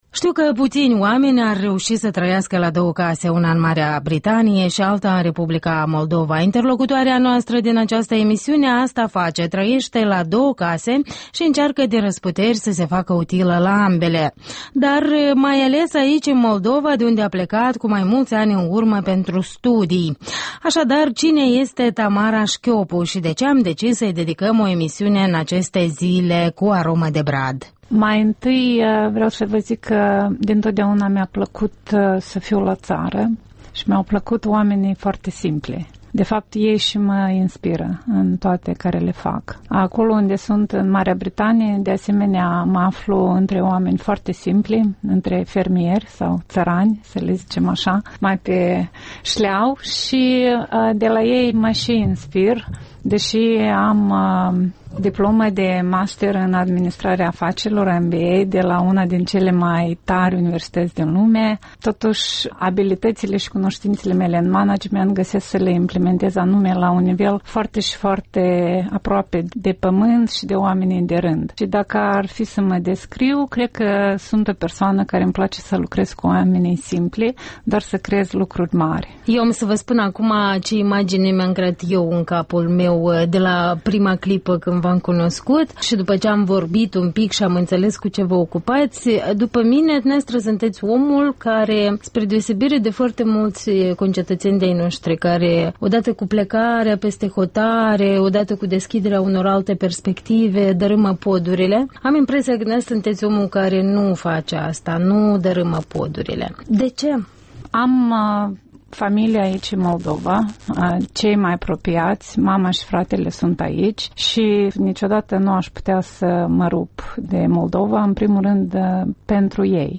Interviuri la EL